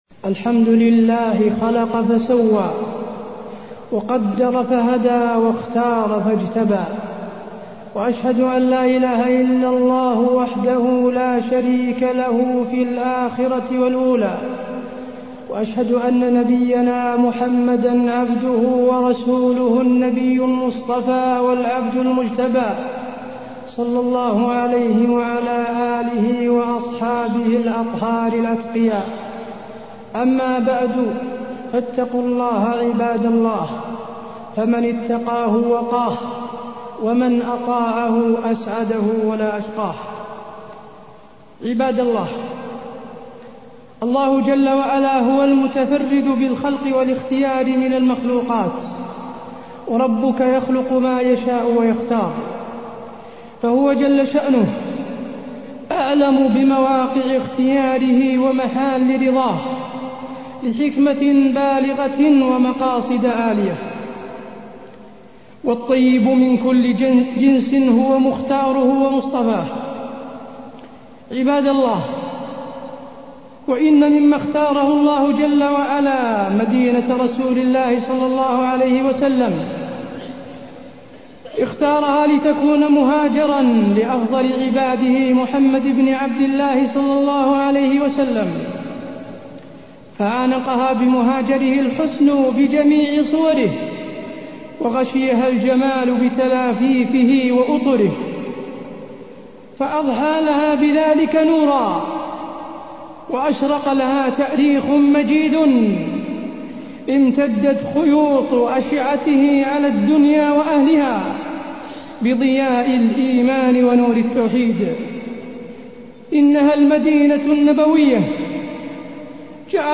تاريخ النشر ١١ جمادى الأولى ١٤٢١ هـ المكان: المسجد النبوي الشيخ: فضيلة الشيخ د. حسين بن عبدالعزيز آل الشيخ فضيلة الشيخ د. حسين بن عبدالعزيز آل الشيخ فضل المدينة